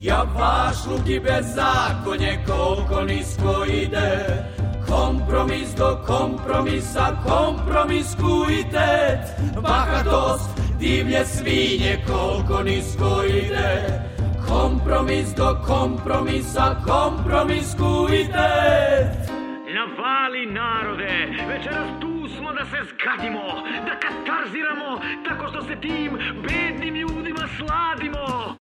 Predstava "Doktor Nušić", odlomak 2